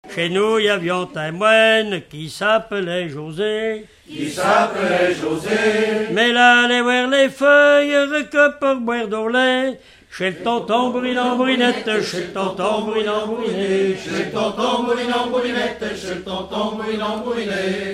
Genre laisse
Enquête Arexcpo en Vendée-C.C. Saint-Fulgent
Pièce musicale inédite